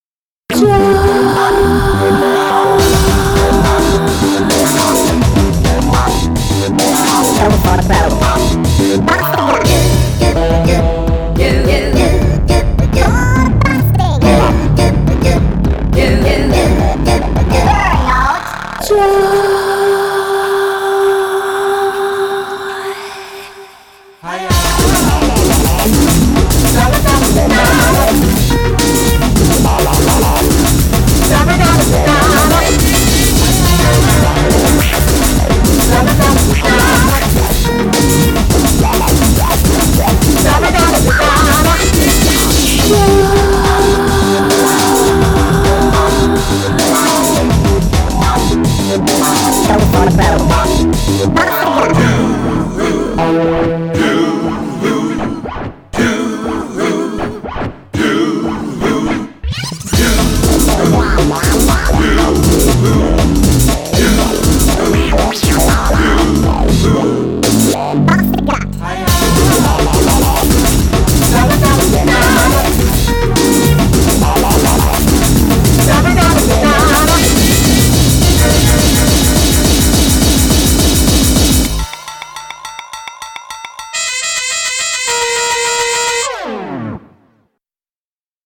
BPM120-210
Audio QualityPerfect (High Quality)
Genre: HARDCORE BREAKBEATS.